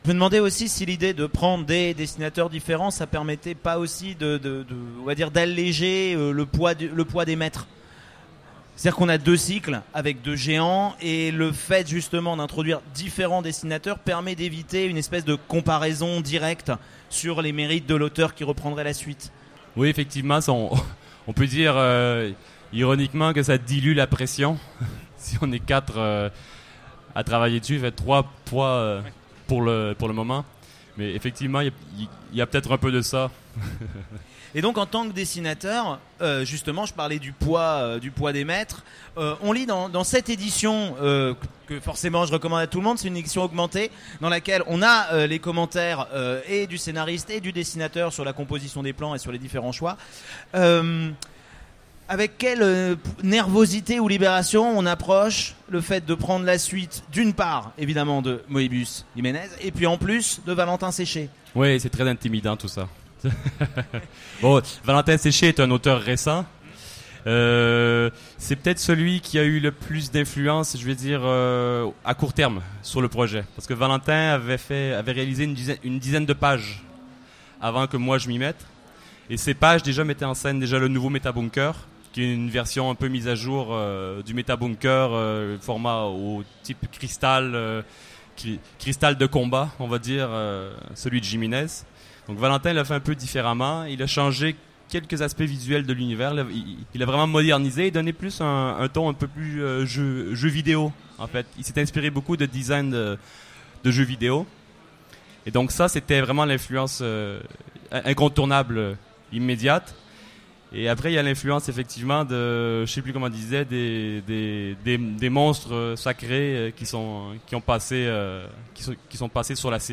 Utopiales 2016 : Conférence Rencontre avec le Méta Baron